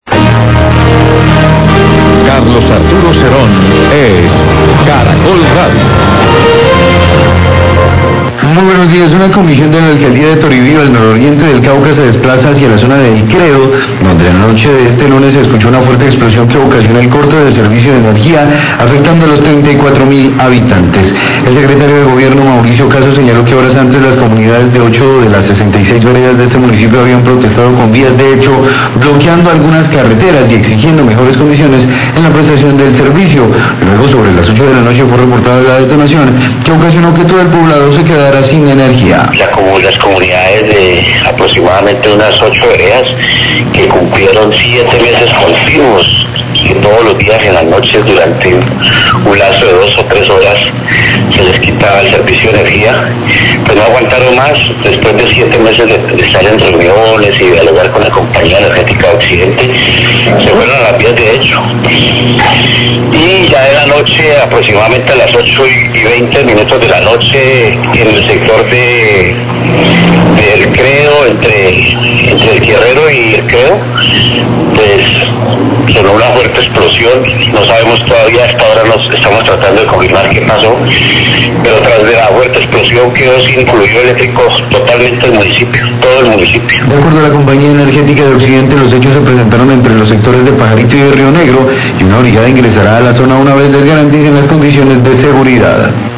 Radio
En la zona de El Credo, Toribío, se escuchó una explosión que ocasionó un corte de energía.Horas antes las comunidades habían efectuado protestas por las vías de hecho bloqueando algunas vías y exigiendo mejores condiciones en la prestación del servicio. Declaraciones del Secretario de Gobierno de Toribío, Mauricio Casas.